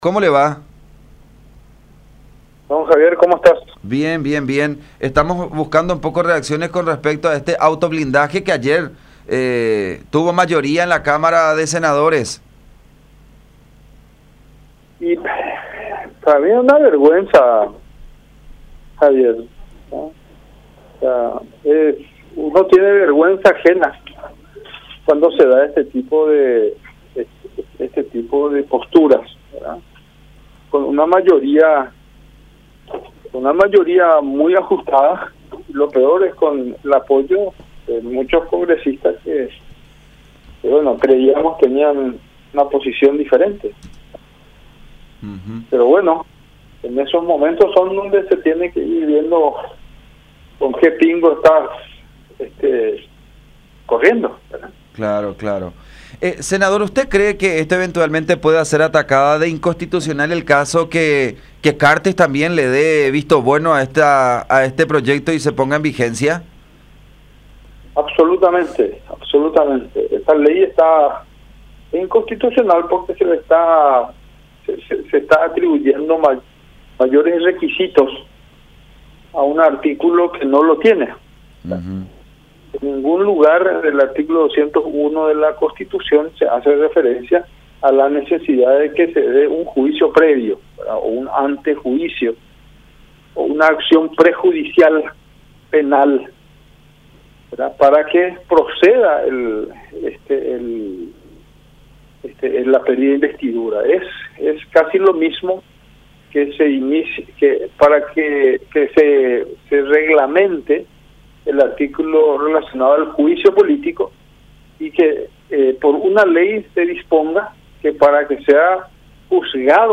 “En ningún lugar del artículo 201 de la Constitución Nacional se hace referencia a la necesidad de que se dé un juicio previo o una acción prejudicial penal para que proceda la pérdida de investidura”, criticó en comunicación con La Unión R800 AM.